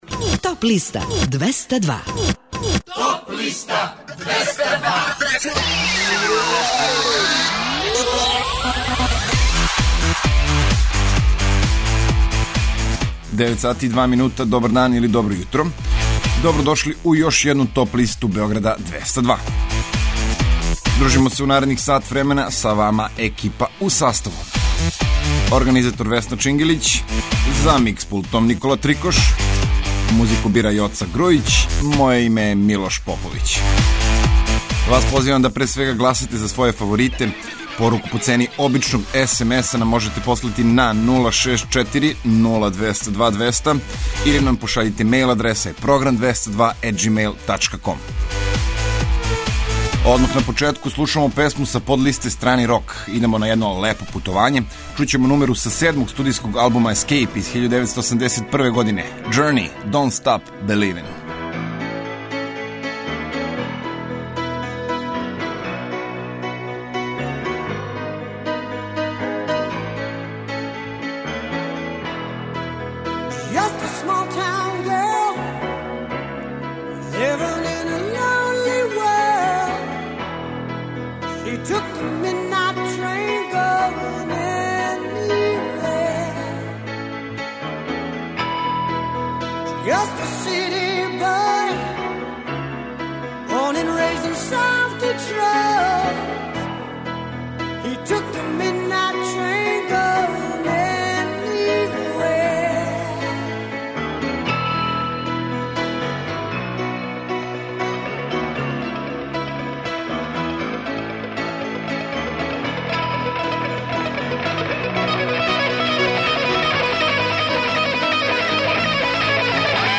Најавићемо актуелне концерте, подсетићемо се шта се битно десило у историји музике у периоду од 28. марта до 1. априла. Емитоваћемо песме са подлиста лектире, обрада, домаћег и страног рока, филмске и инструменталне музике, попа, етно музике, блуза и џеза, као и класичне музике.